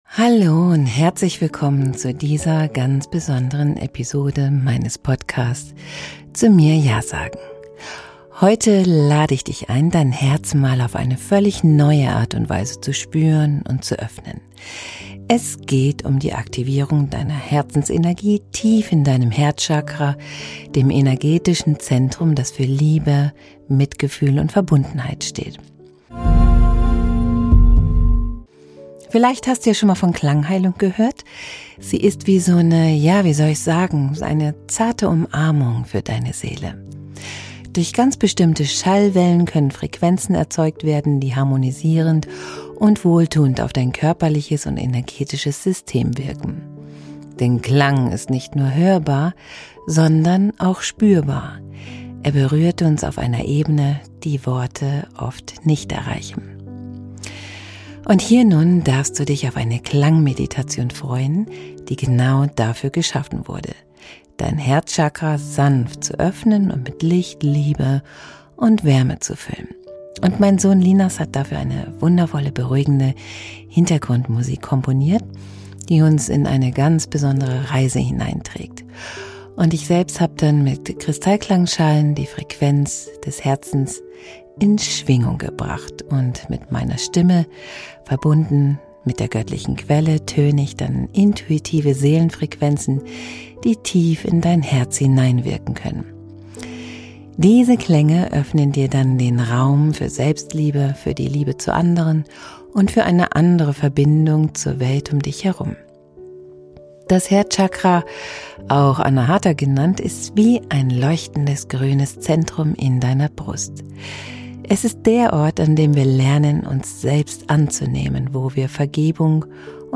In meiner neuesten Podcast-Episode erwartet dich eine ganz besondere Klangmeditation, die dein Herzchakra öffnet und mit Liebe, Wärme und Leichtigkeit füllt. Lass dich von intuitivem Seelengesang und den magischen Klängen von Kristallklangschalen in eine Welt tragen, in der Selbstliebe und Verbunden...